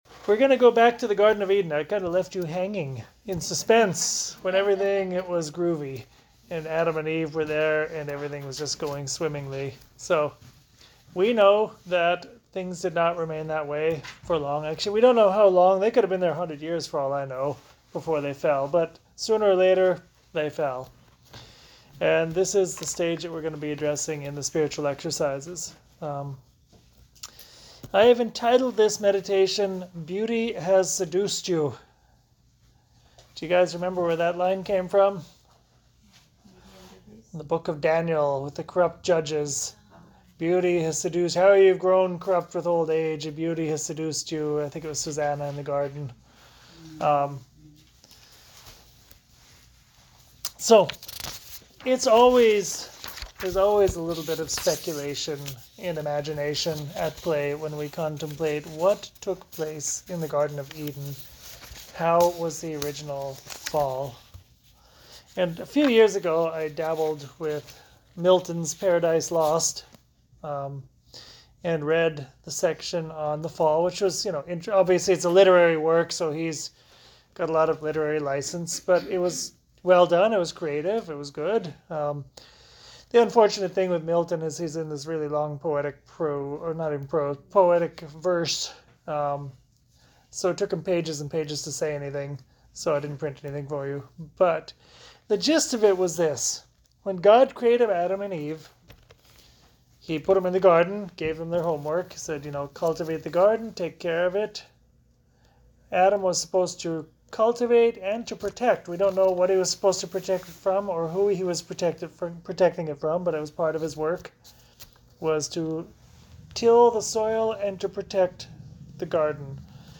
meditation from the Women’s Spiritual Exercise retreat